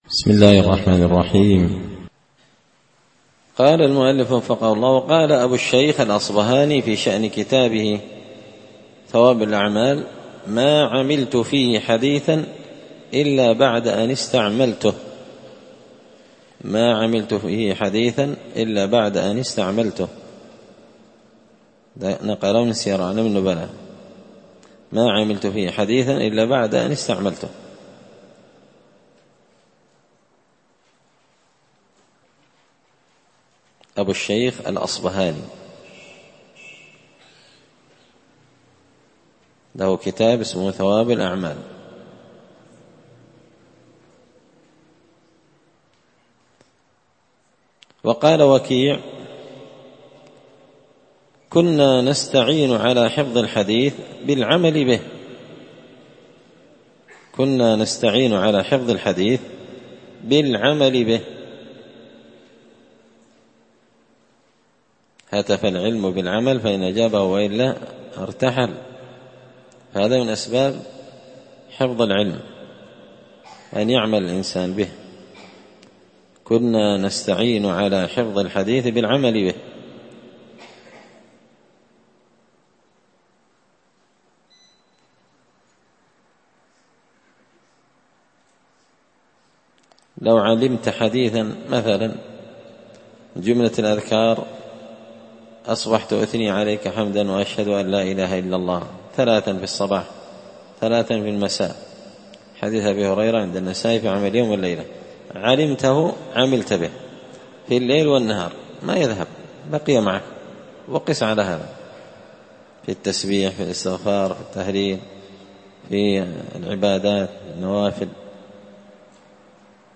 الدرس السابع عشر (17) تابع للأدب الحادي عشر العمل بالعلم